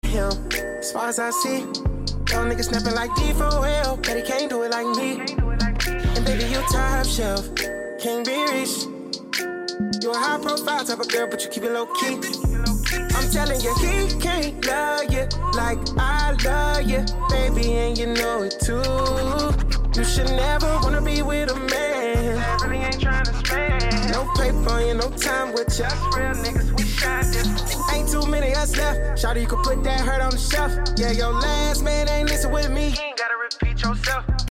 late 90’s feel back to R&B music